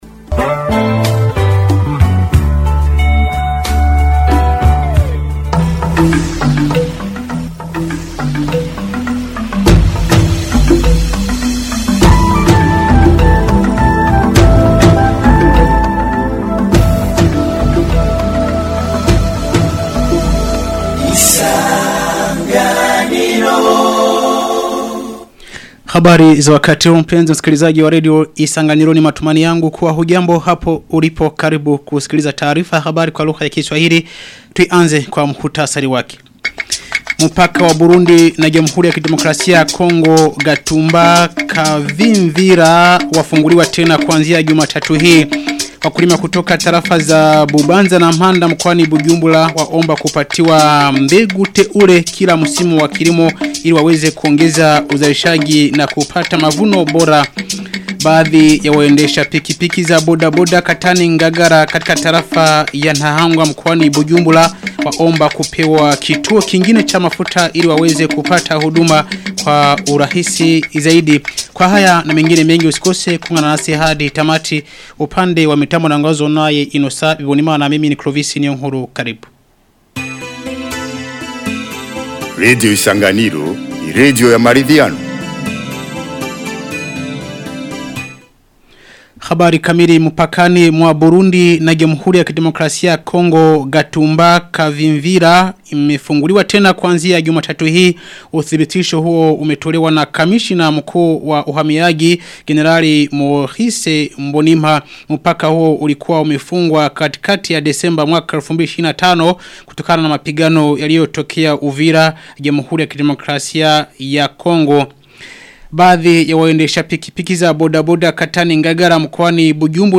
Taarifa ya habari ya tarehe 23 Februari 2026